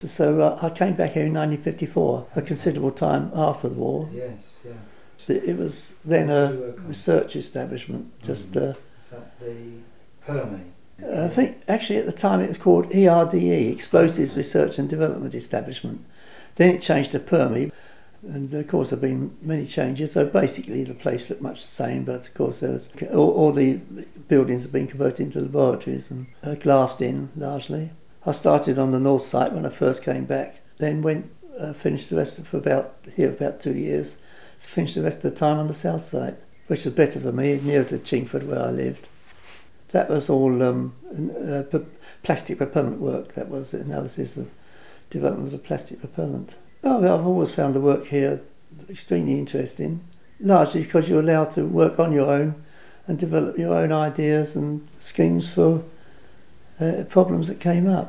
WAOH - Waltham Abbey Oral History